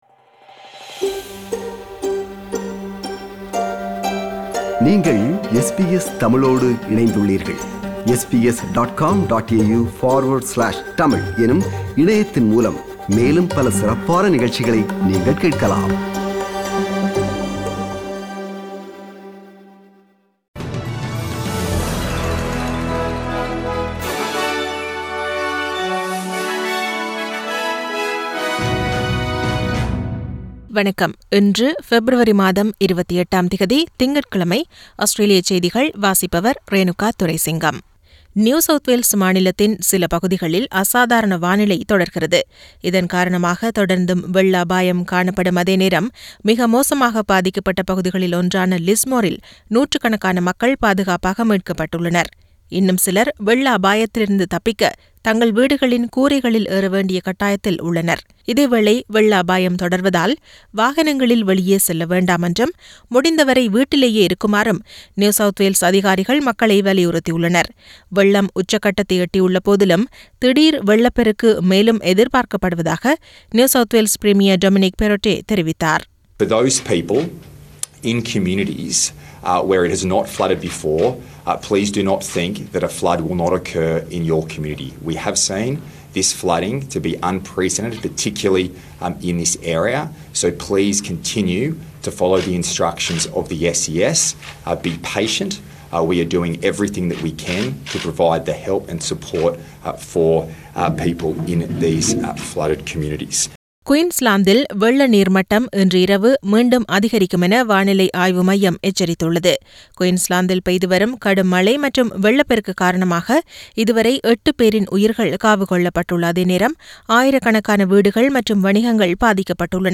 Australian news bulletin for Monday 28 Feb 2022.